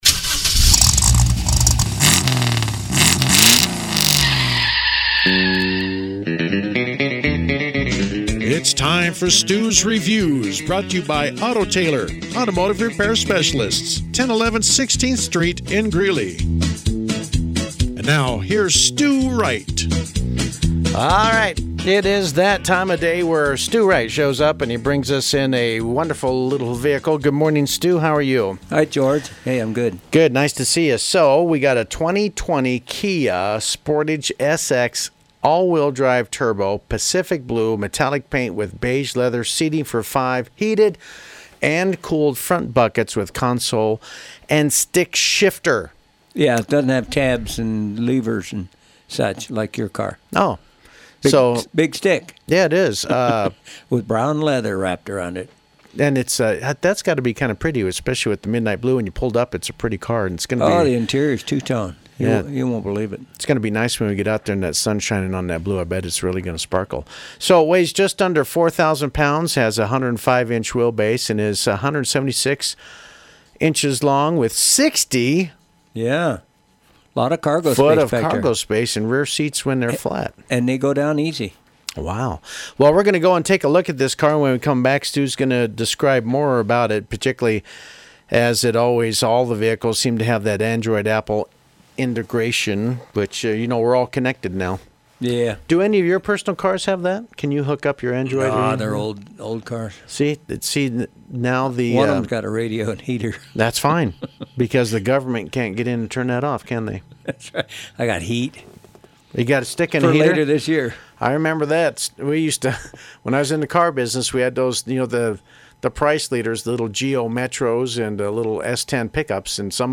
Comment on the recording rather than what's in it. helped me analyze the KIA at the Pirate studios: